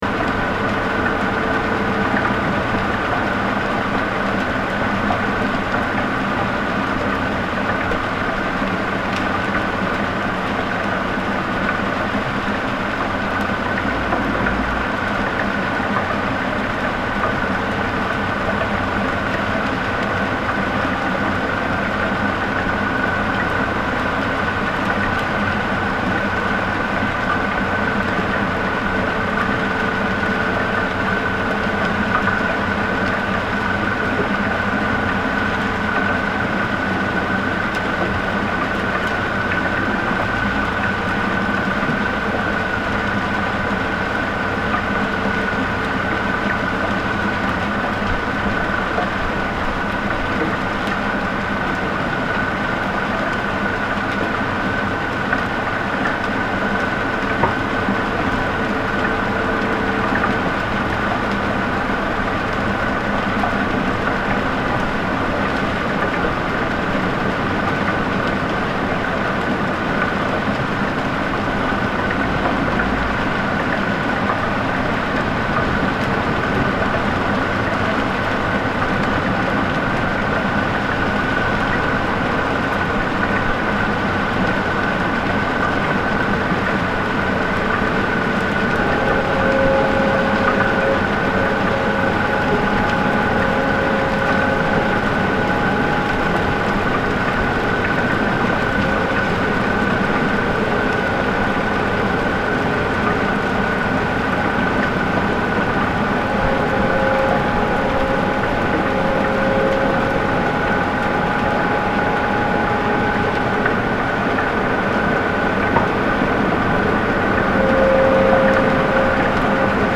Field Recording Series